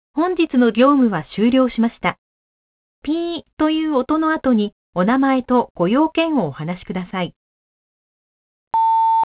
■留守番電話１